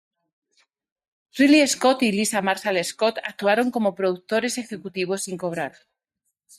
pro‧duc‧to‧res
/pɾoduɡˈtoɾes/